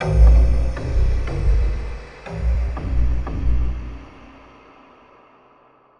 Index of /musicradar/impact-samples/Processed Hits
Processed Hits 07.wav